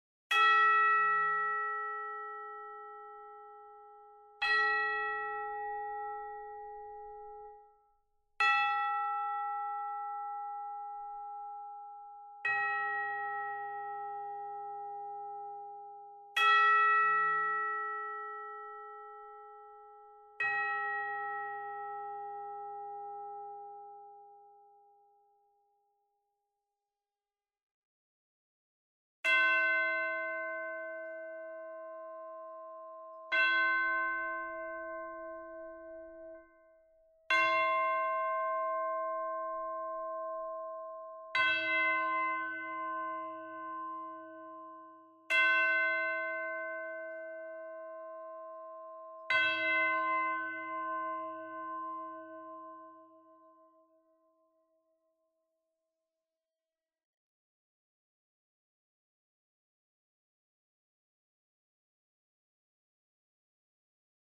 Сравнил с тремя другими - нижние гармоники у синхронов по-другому настроены, как-будто "в тональности" Порядок звучания: 1) EW orchestral 2) Hollywood 3) CinePerc 4) Synchron 5) EW orchestral (снова) 6) Synchron (снова)
Tabular bells menu.mp3